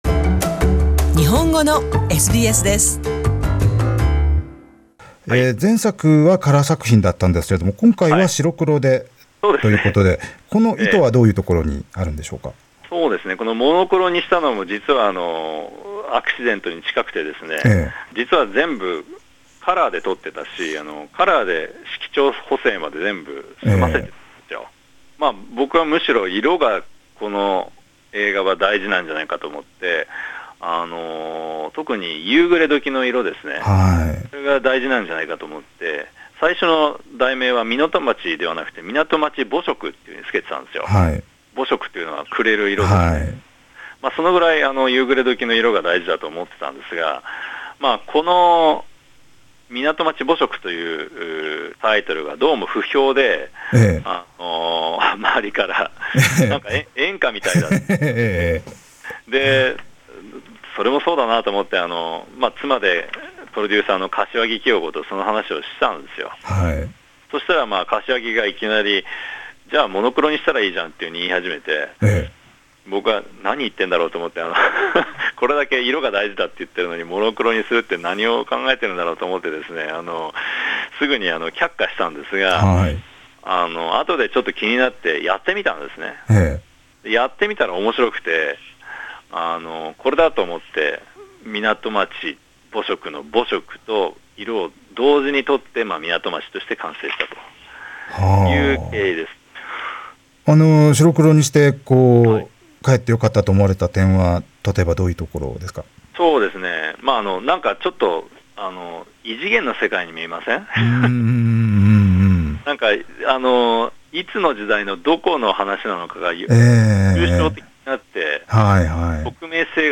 観察映画『港町』の世界 想田和弘インタビュー パート2